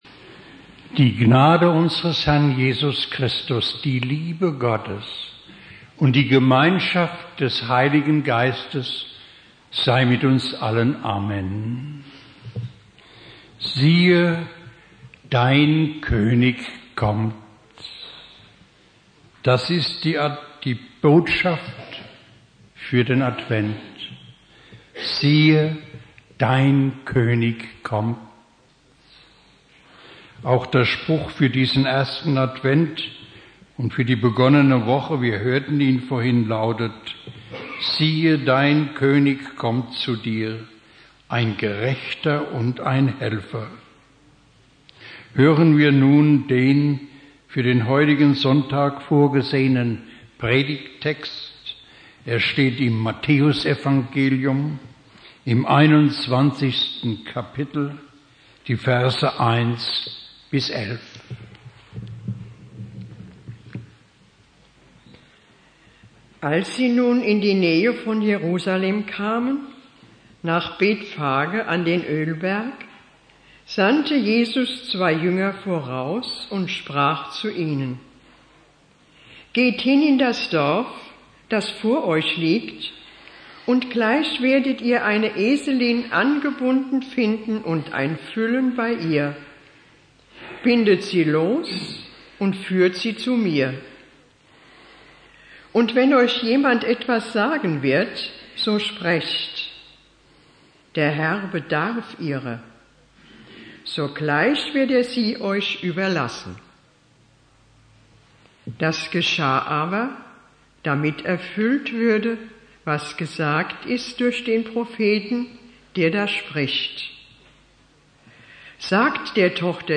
Predigt
1.Advent Prediger